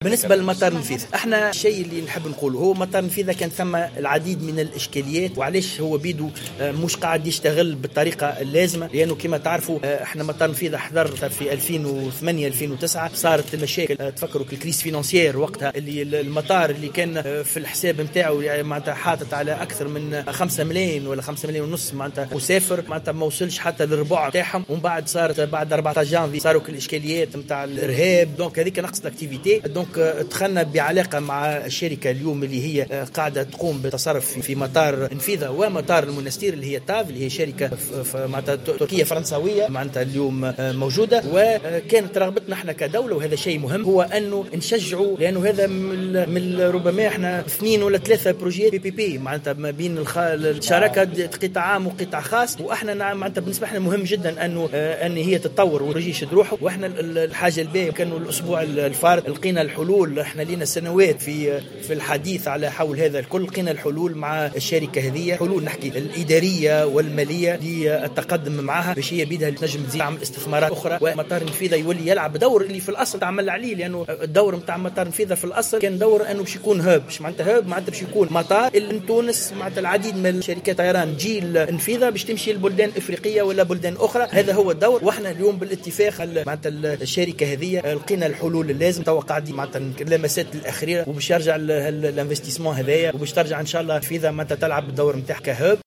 وأضاف الوزير في تصريح لمراسلة الجوهرة اف ام خلال لقاء حواري إقليمي حول النقل الجوي والبحري نحو البلدان الإفريقية اليوم الخميس بسوسة، أنه بعد حل جميع الإشكاليات سيعود المطار إلى سالف نشاطه.